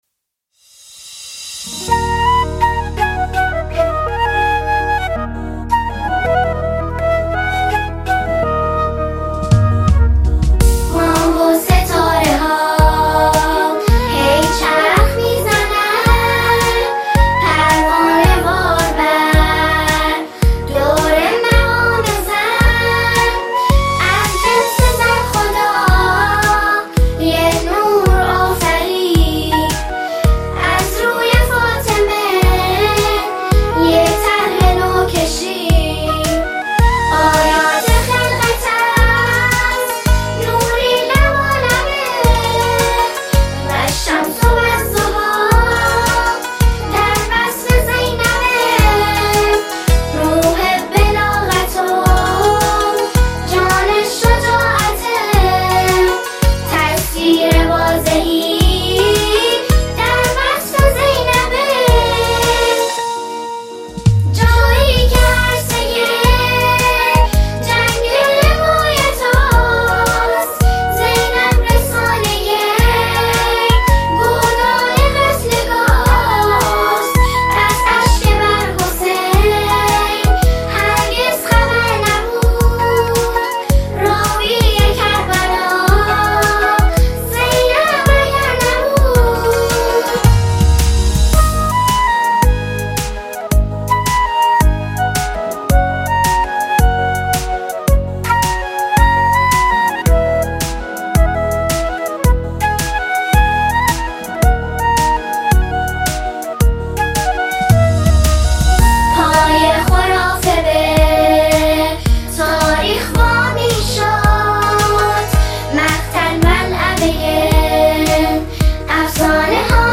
سرودی عمیق و تأثیرگذار